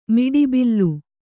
♪ miḍi billu